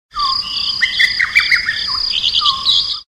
알림음 8_자연의소리.ogg